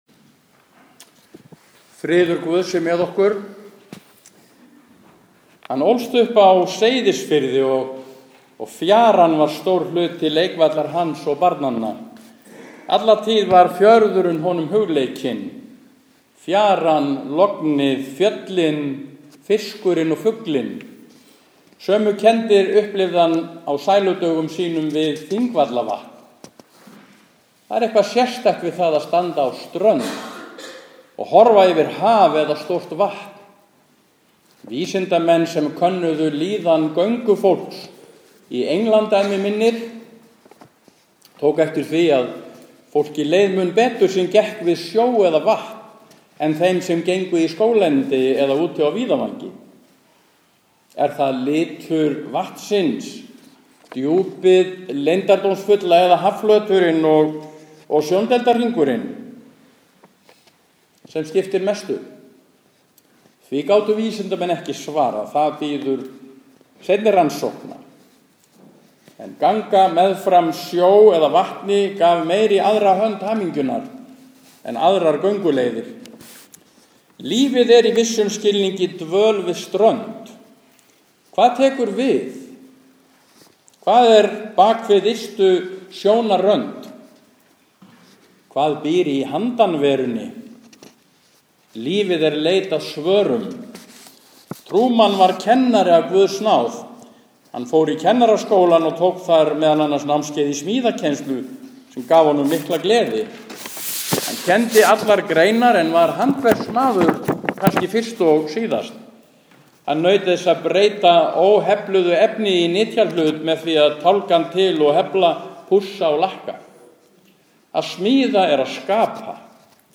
Minningarorð
Útför frá Kópavogskirkju